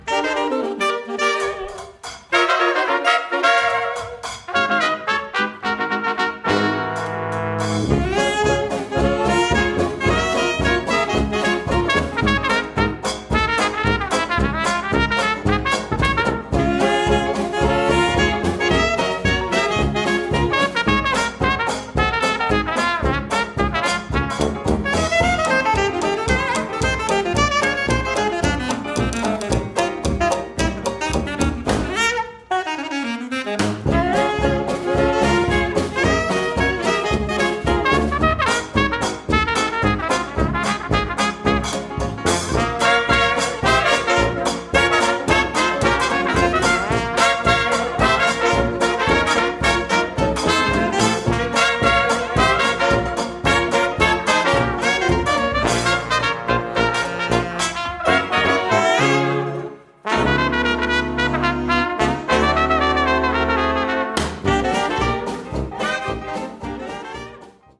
trompettes
trombone
saxophone alto, clarinette
piano
banjo, guitare ténor
contrebasse
batterie